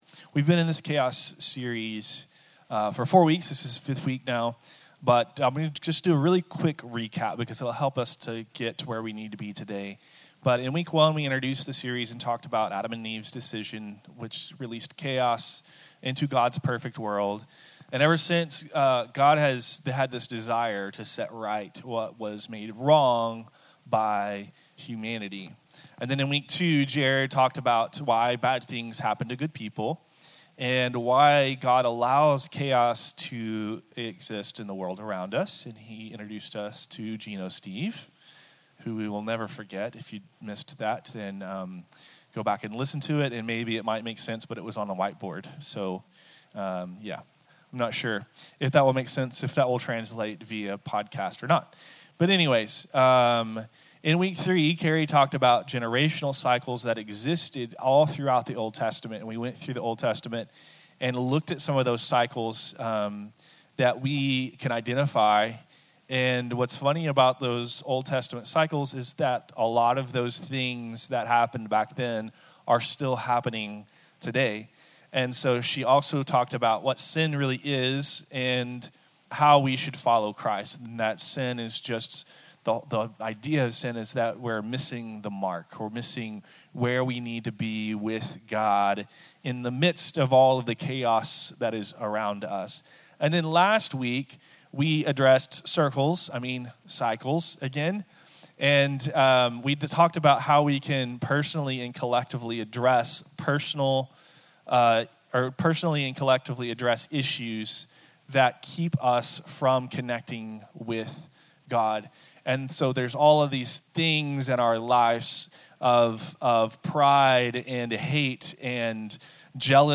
Sermons | Project Community